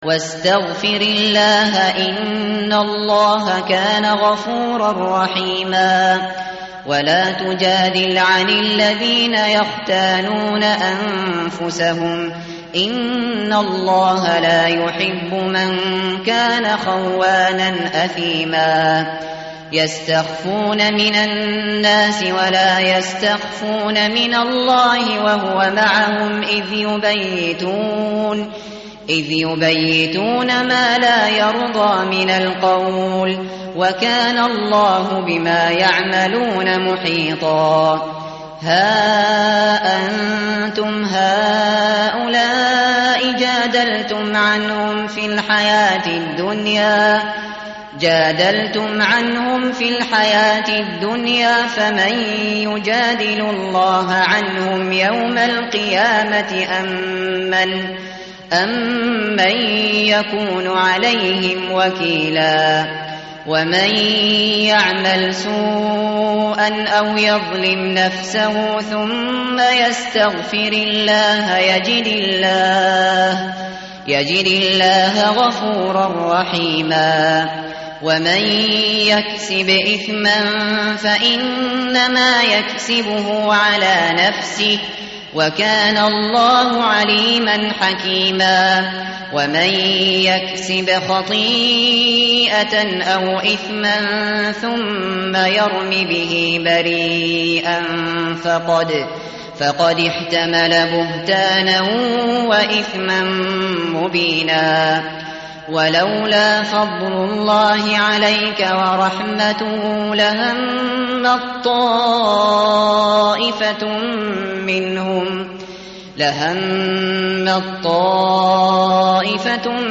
متن قرآن همراه باتلاوت قرآن و ترجمه
tartil_shateri_page_096.mp3